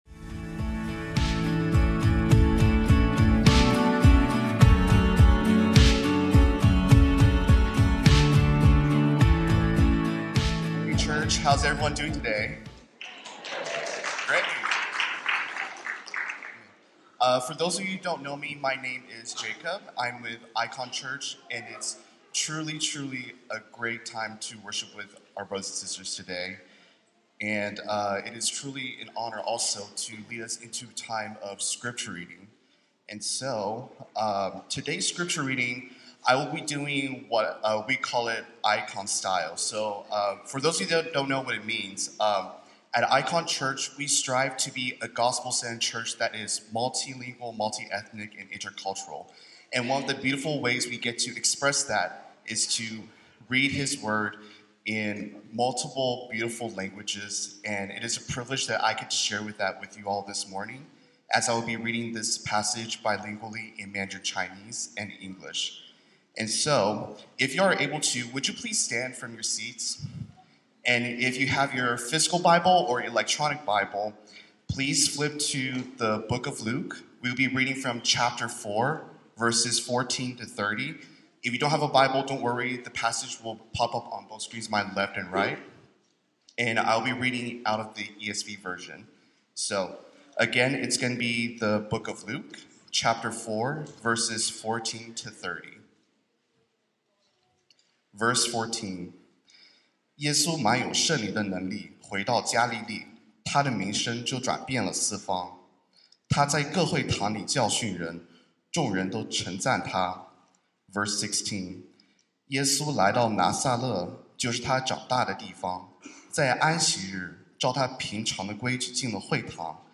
This sermon explores the heart of that moment and why it still matters today.
Through engaging storytelling, real-life reflection, and a powerful panel discussion among three pastors from diverse churches, this message addresses: